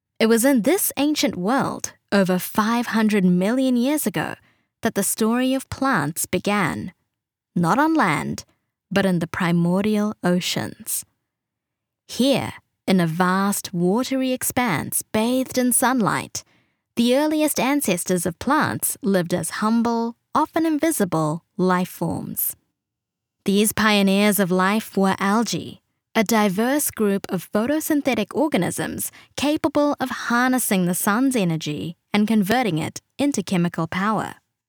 NARRATION 😎